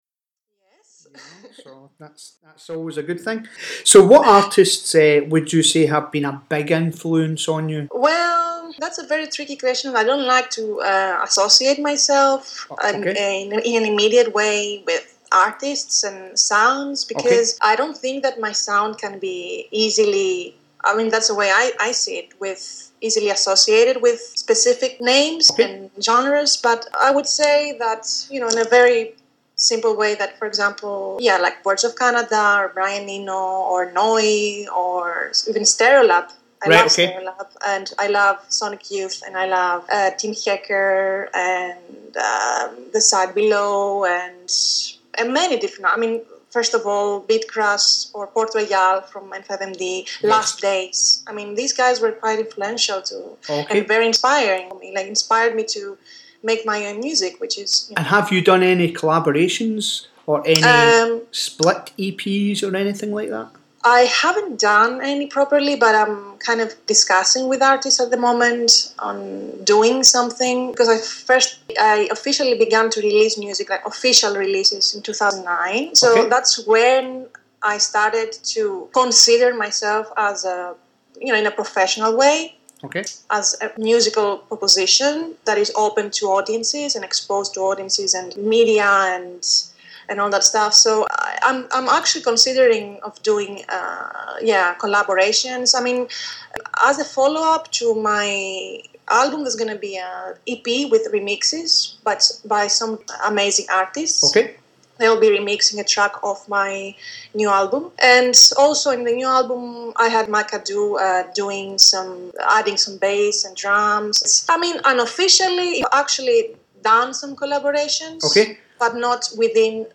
DALOT INTERVIEW PART 2
dalot-interview-part-2.mp3